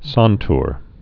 (säntr)